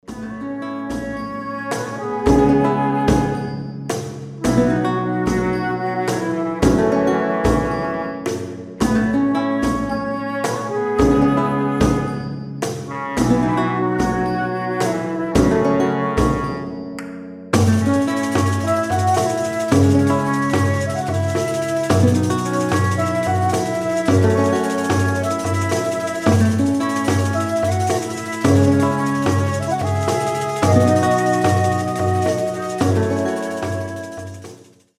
гитара
спокойные
без слов
Cover
фолк
Флейта
Средневековый бардкор кавер на известную песню